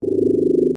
Robot Trilling
Robot Trilling is a free sfx sound effect available for download in MP3 format.
Robot Trilling.mp3